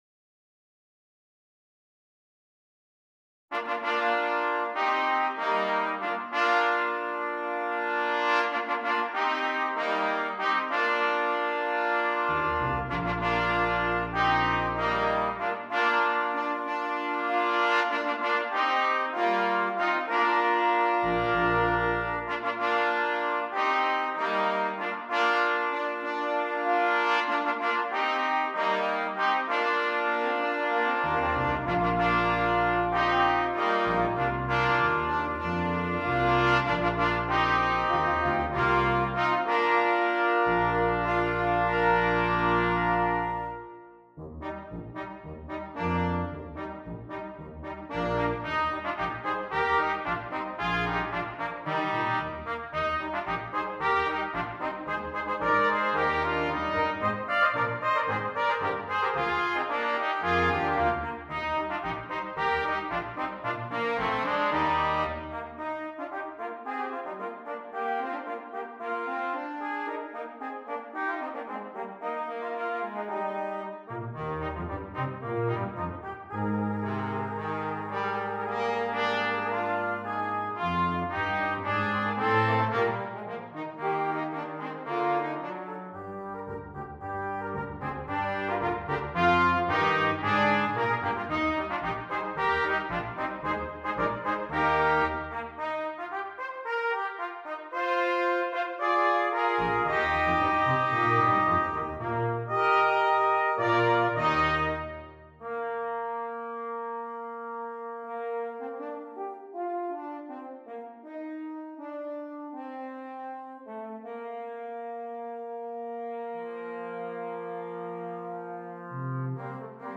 Brass Quintet (optional Percussion)